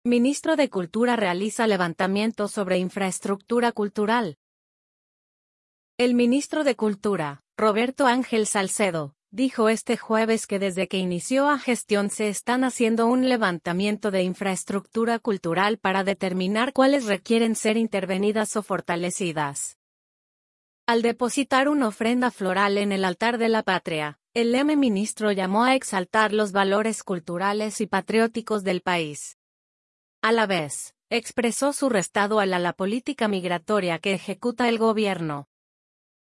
Al depositar un ofrenda floral en el altar de la patria, el m ministro llamó a exaltar los valores culturales y patrióticos del país.